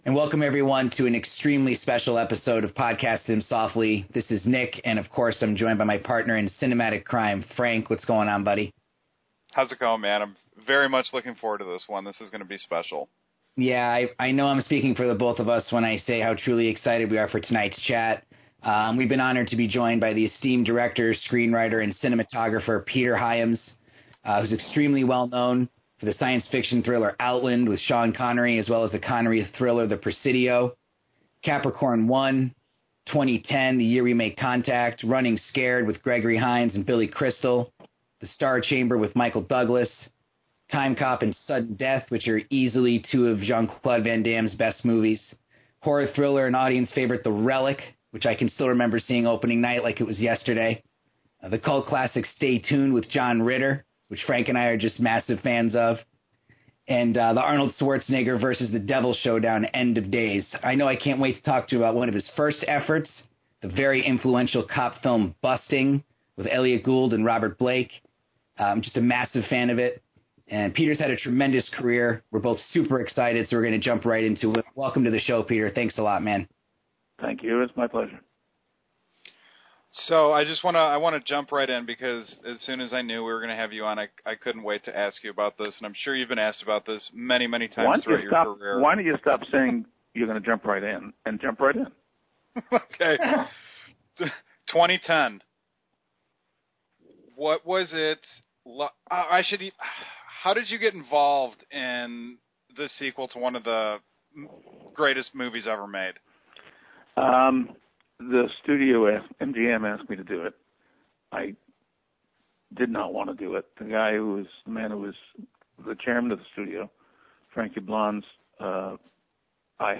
Podcasting Them Softly is extraordinarily excited to present a chat with cinematic legend Peter Hyams!
You can also hear us talk excitedly about one of Peter ‘s early efforts, the trendsetting cop film Busting, with Elliot Gould and Robert Blake, and discuss how that film began to give a particular genre a new and modern feel. Peter has had a tremendous career, and we were beyond lucky and honored to have him as a guest on the show.